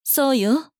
大人女性│女魔導師│リアクションボイス
肯定・否定